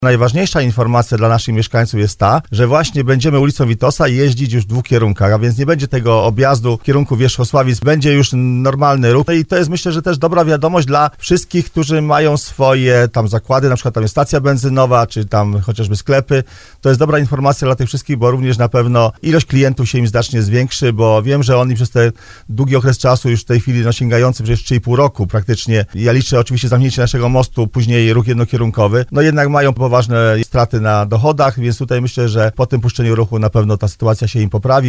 Starosta tarnowski Roman Łucarz podkreśla, że umożliwienie kierowcom jazdy w obu kierunkach na Witosa to komfort dla kierowców i nadzieja dla lokalnych przedsiębiorców na odbudowanie swoich biznesów, które mocno ucierpiały przez ciągnące się remonty.